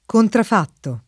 [ kontraf # tto ]